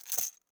Anarchy / sound / weapons / arccw_ue / m249 / belt1.ogg